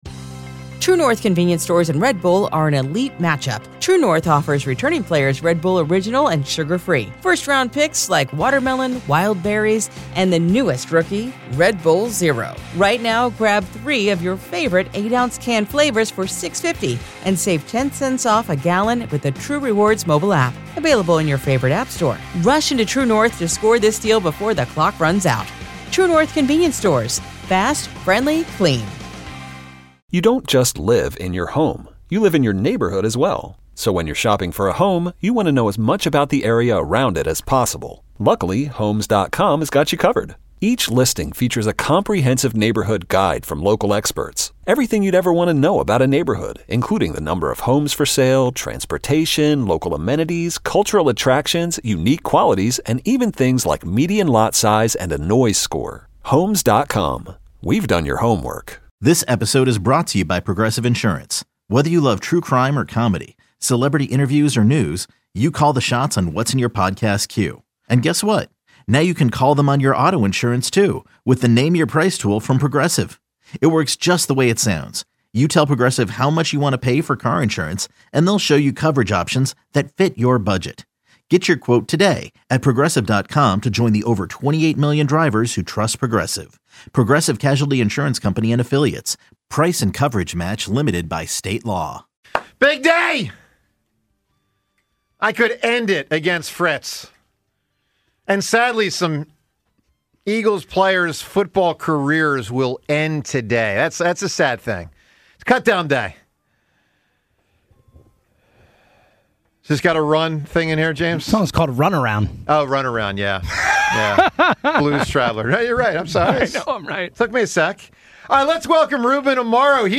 Ruben Amaro joins the 94 WIP Morning Show LIVE In-Studio. Should fans be worried about the Phillies big loss in Queens last night?
How will the Eagles roster shake out? All this plus calls!